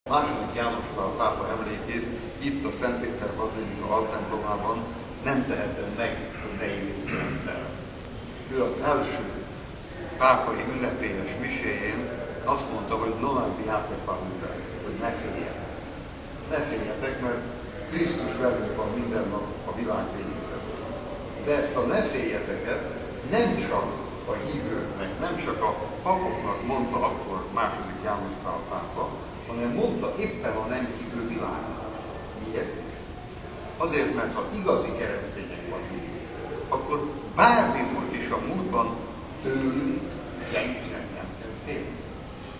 „A pap küldetése a nagylelkű szeretet sajátos formája” – Erdő Péter bíboros homíliája a vatikáni magyar kápolnában
Kedden reggel a vatikáni Szent Péter bazilika Magyarok Nagyasszonya kápolnájában a szokásos reggel 8 órakor kezdődő szentmisét ezúttal Erdő Péter bíboros, esztergom-budapesti prímás mutatta be.